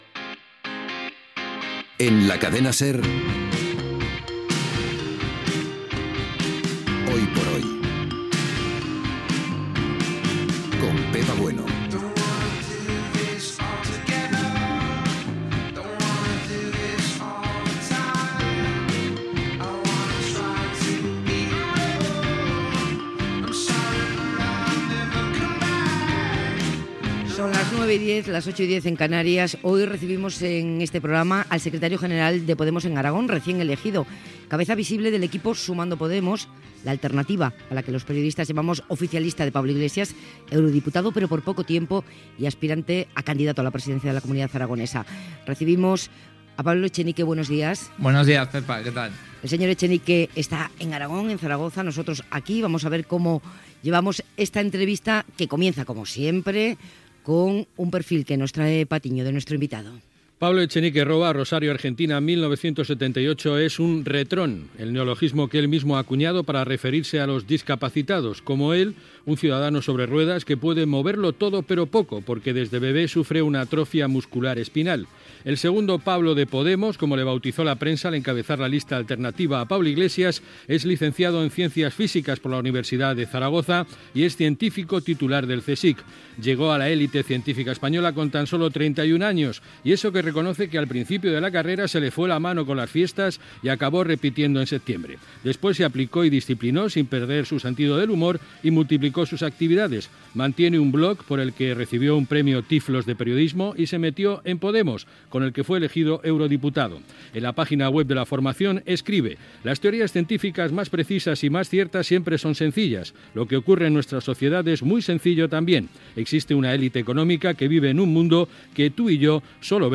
Indicatiu del programa, entrevista al polític Pablo Echenique, secretari general de Podemos Aragón.
Info-entreteniment